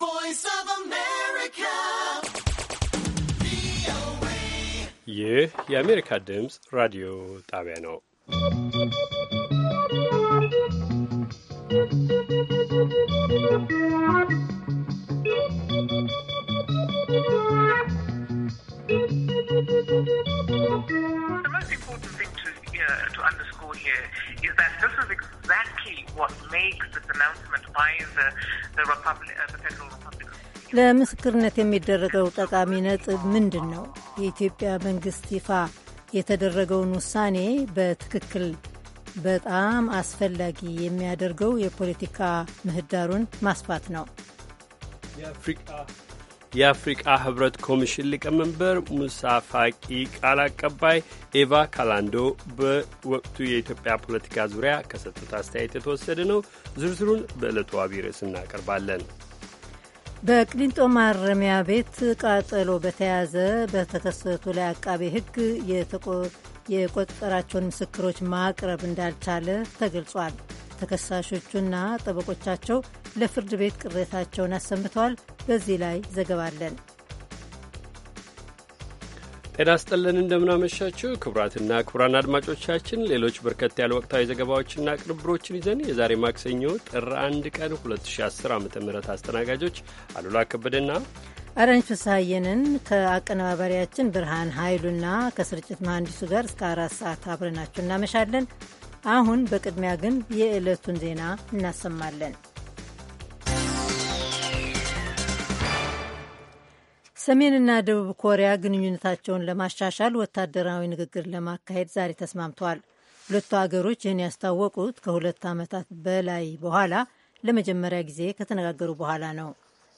ማክሰኞ፡- ከምሽቱ ሦስት ሰዓት የአማርኛ ዜና